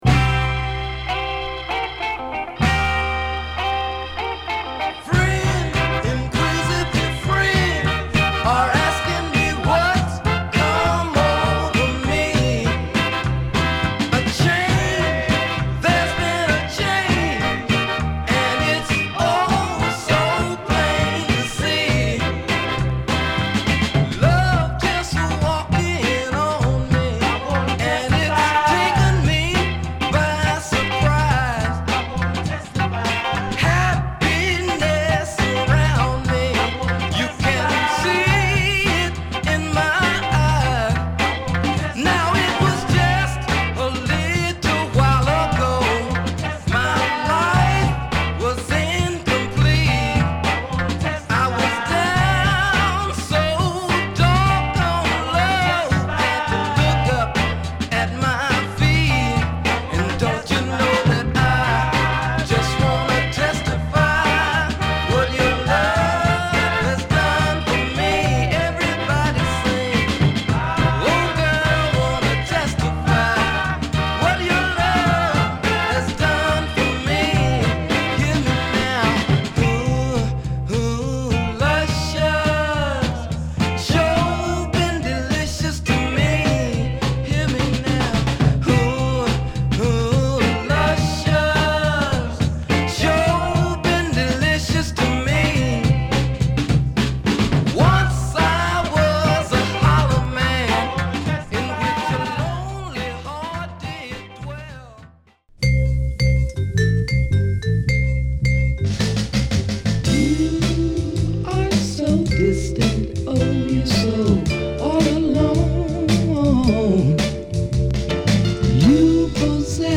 骨太のドラムの上でコーラスワークを乗せるデトロイトソウル！！